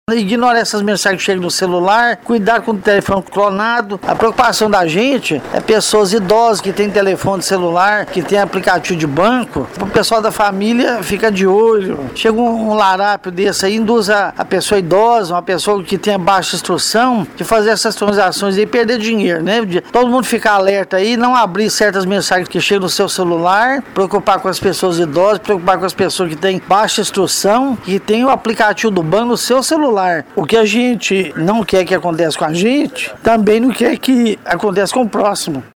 Trabalhador